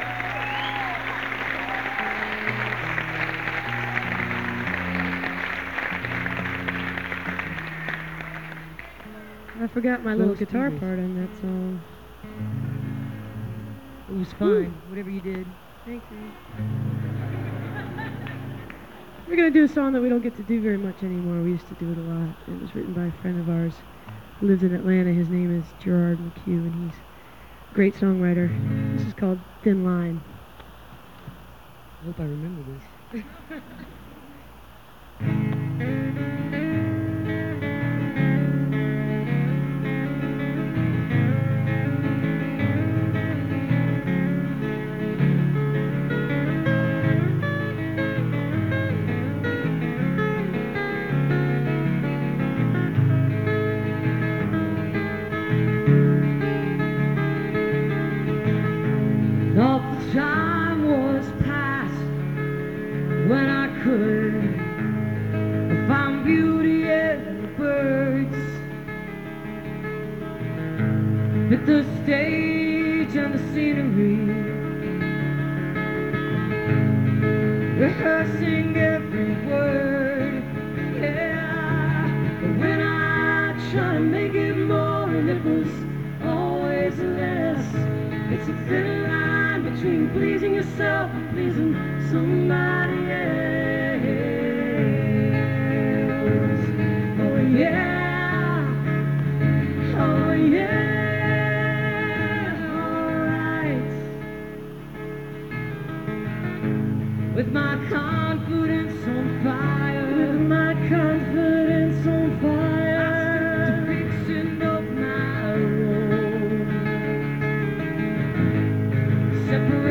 (radio broadcast)